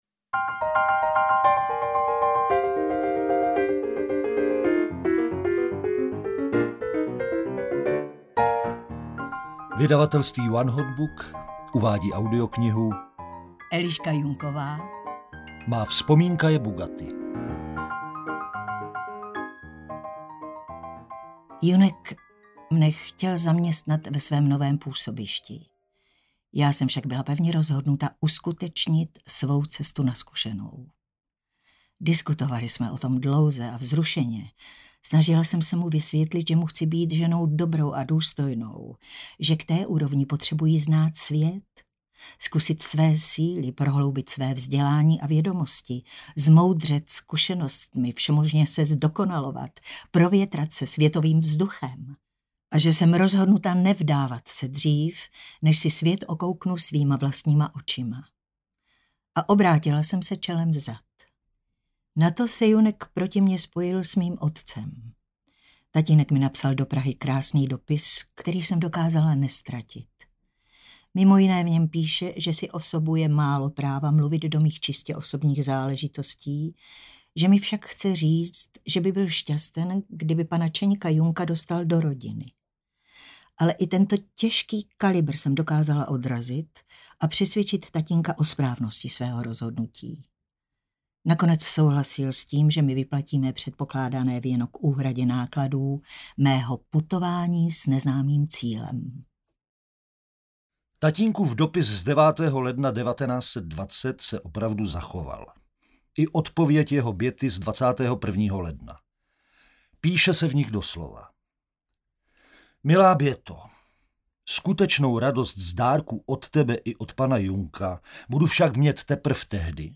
Audio knihaMá vzpomínka je bugatti
Ukázka z knihy
• InterpretHana Maciuchová, Jaromír Dulava